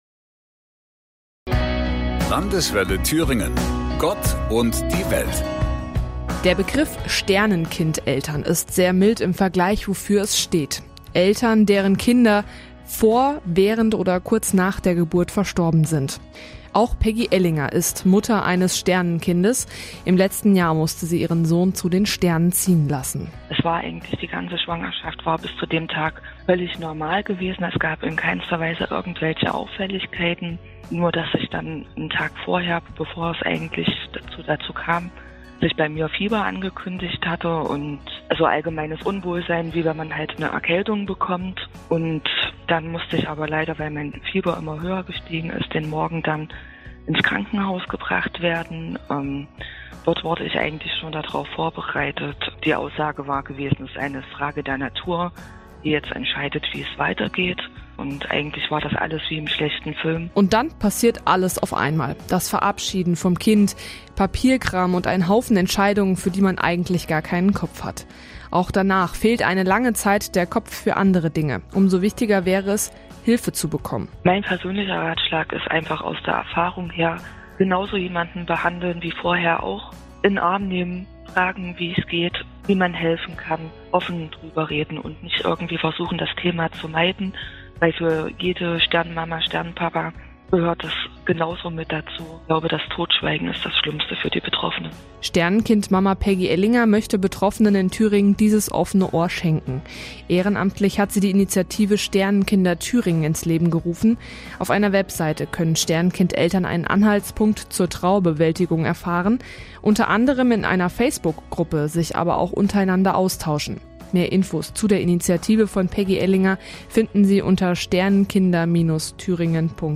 Telefoninterview Landeswelle Thüringen: